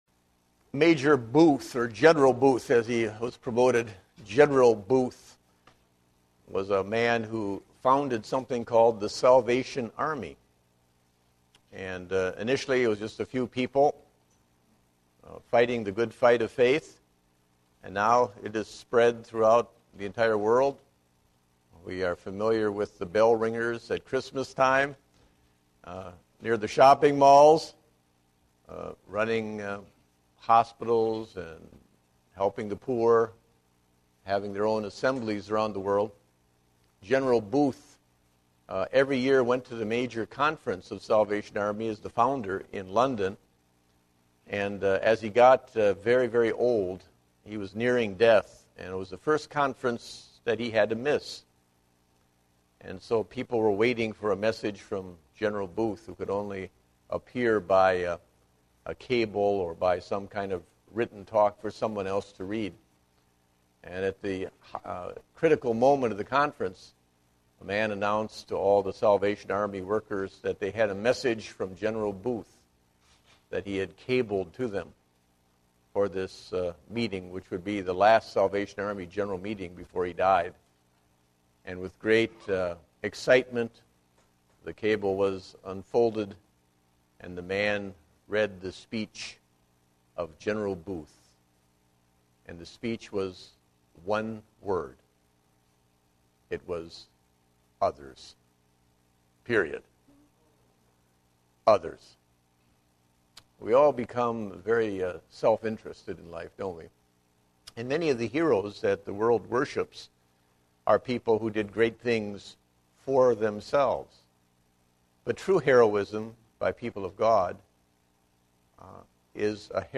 Date: June 27, 2010 (Adult Sunday School)